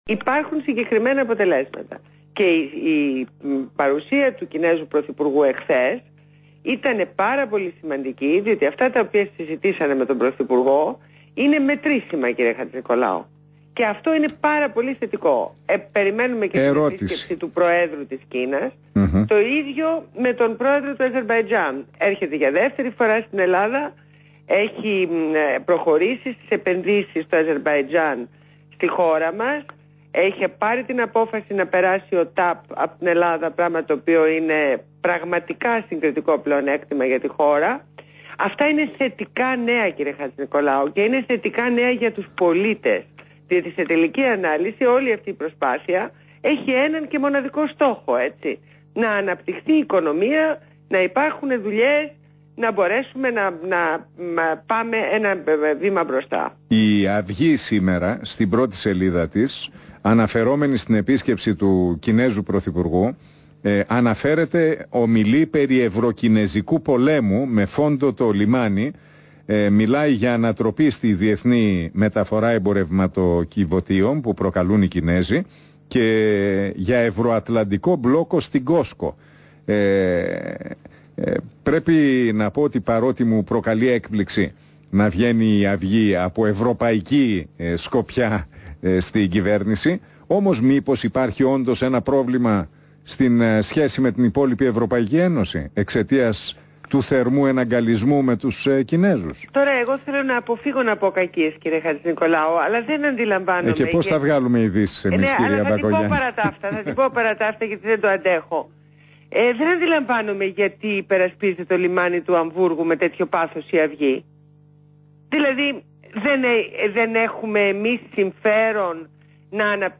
Συνέντευξη στην εκπομπή του Νίκου Χατζηνικολάου στο ραδιόφωνο του Real 97,8fm.